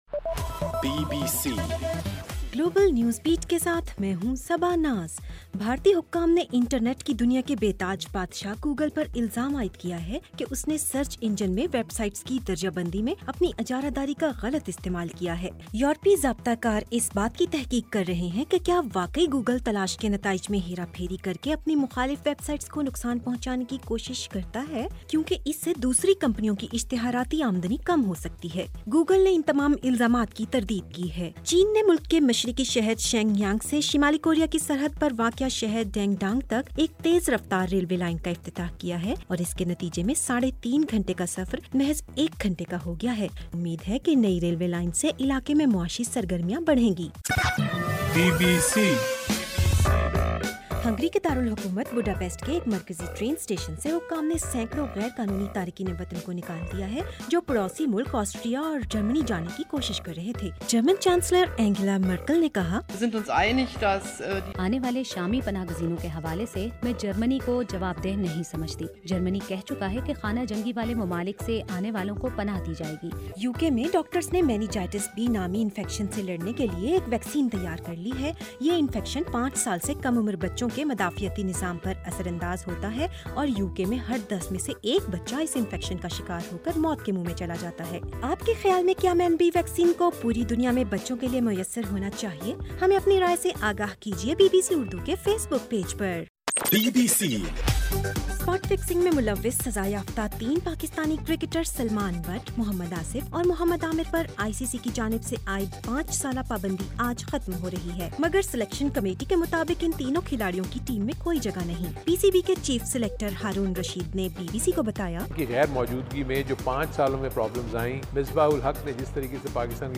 ستمبر 1:رات12 بجے کا گلوبل نیوز بیٹ بُلیٹن